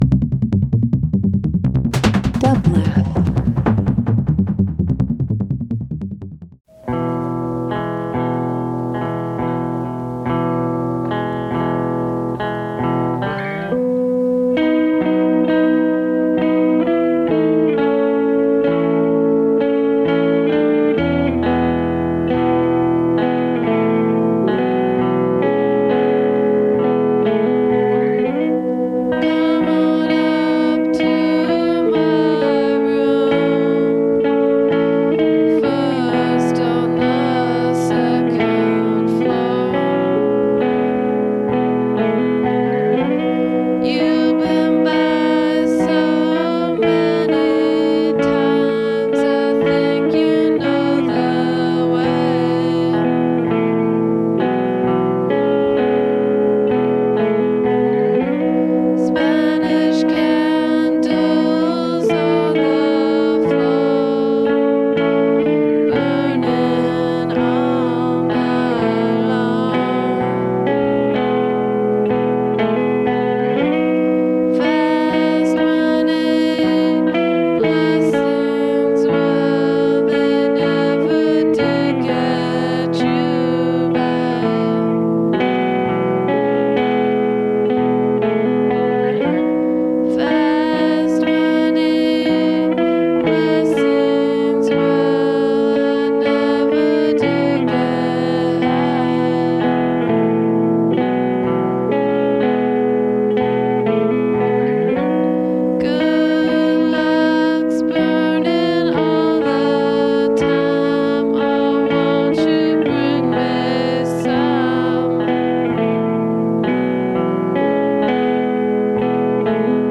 Hip Hop Indie Post Rock Shoegaze